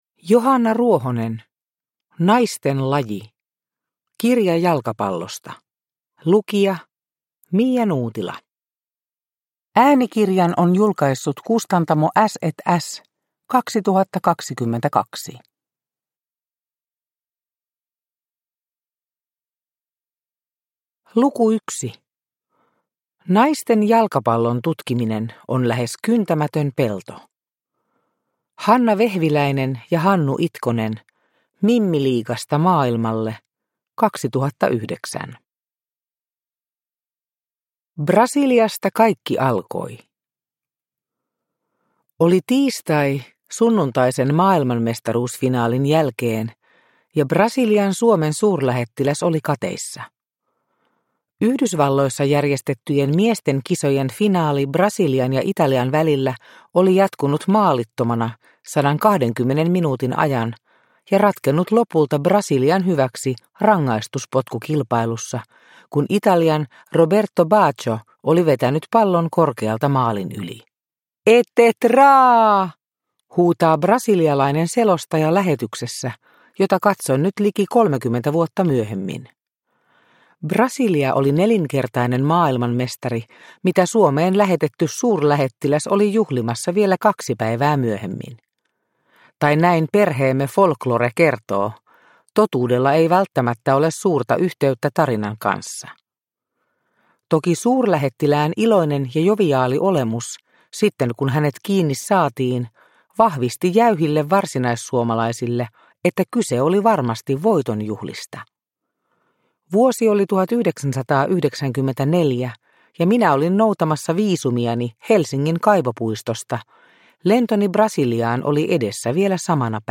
Naisten laji – Ljudbok – Laddas ner